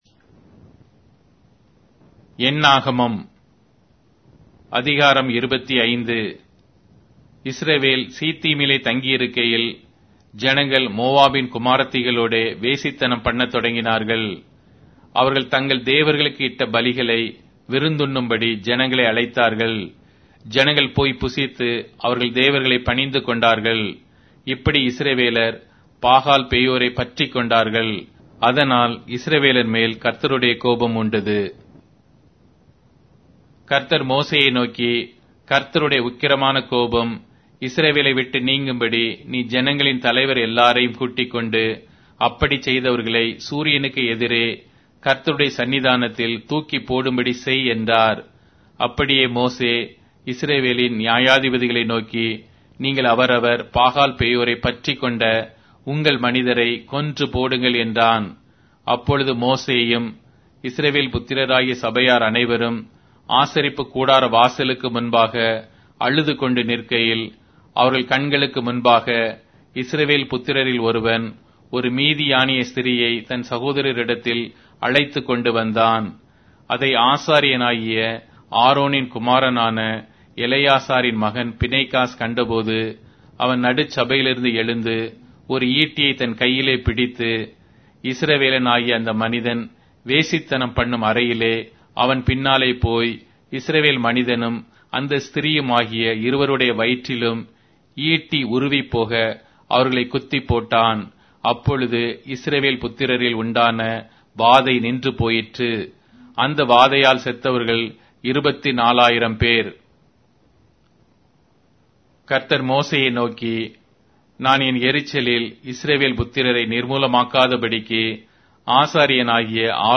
Tamil Audio Bible - Numbers 33 in Irvgu bible version